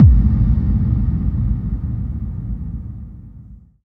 VEC3 FX Reverbkicks 05.wav